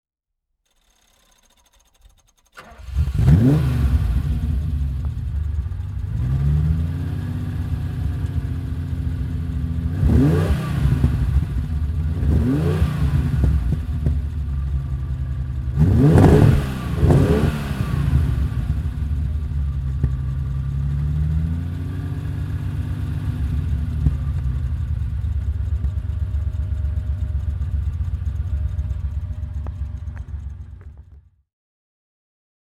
Ferrari 365 GTC/4 (1971) - Starten und Leerlauf